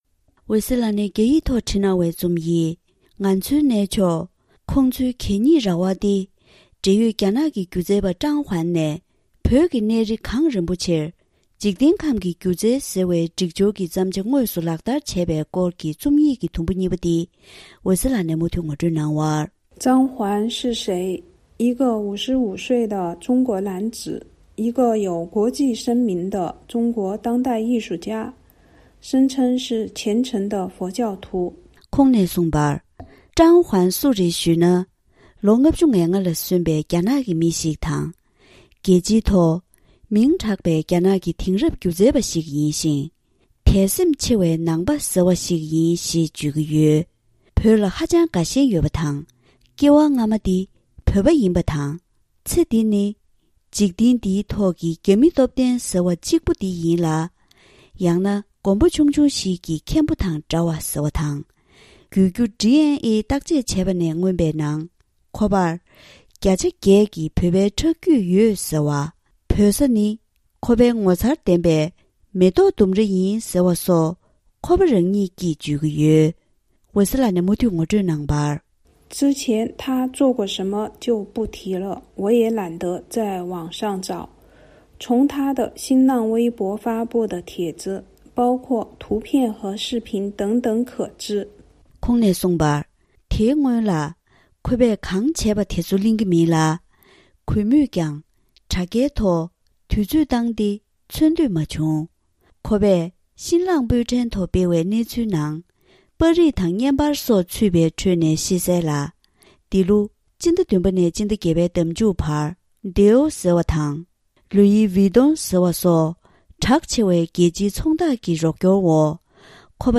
ང་ཚོའི་གནས་མཆོག་དང་ཁོང་ཚོའི་གད་སྙིགས་རྭ་བ། དུམ་བུ་གཉིས་པ། སྒྲ་ལྡན་གསར་འགྱུར།